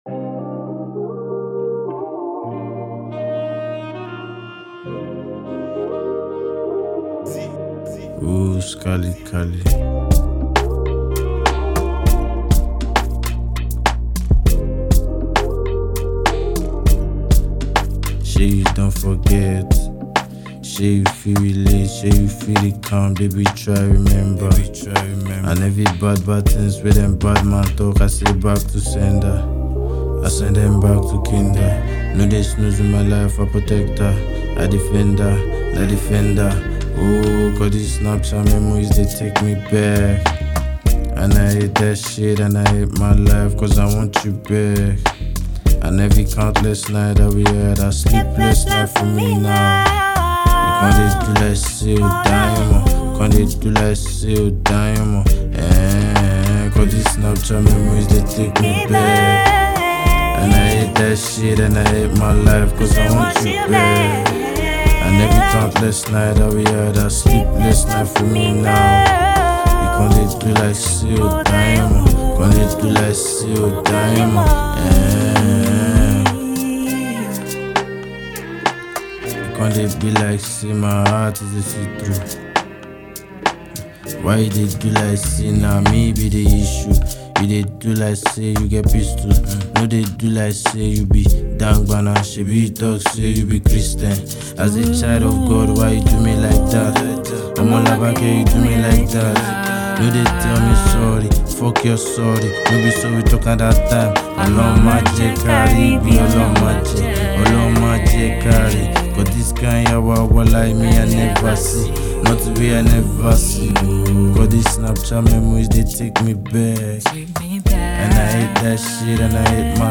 soul-touching tune
raw emotion and smooth vocals